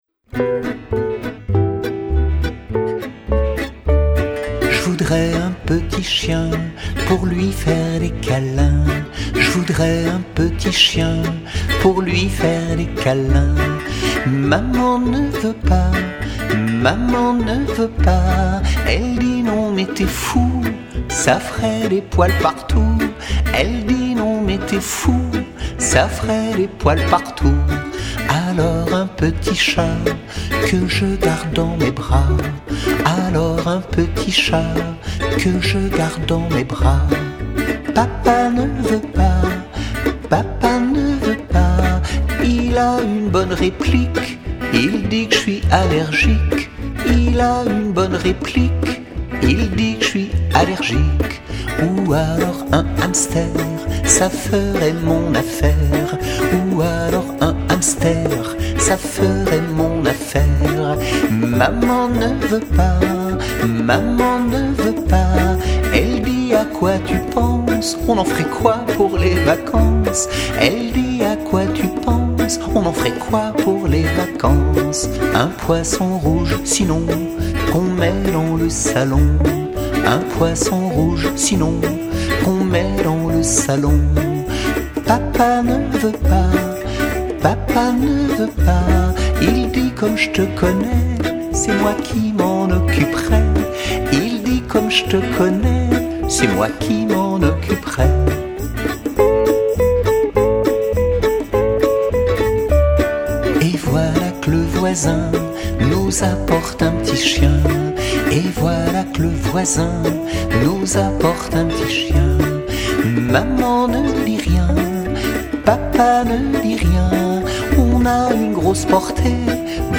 Chanson au format MP3